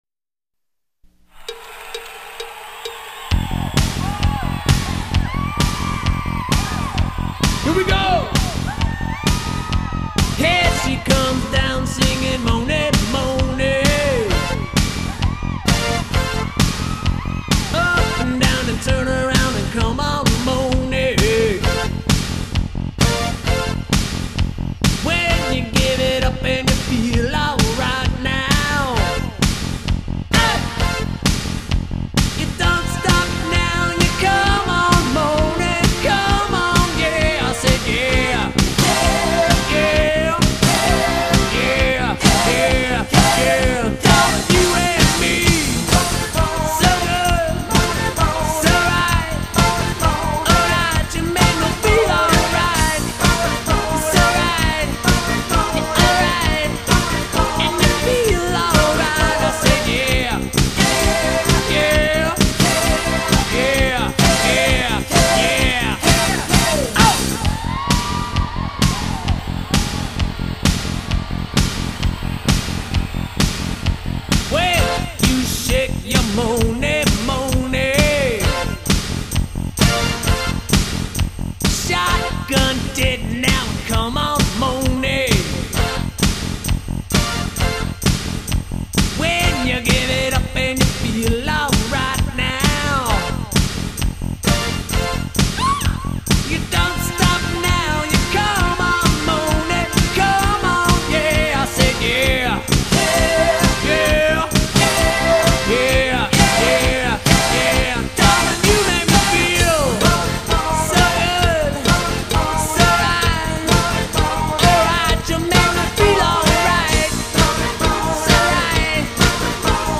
(All Time Great Rock & Roll Dance Music)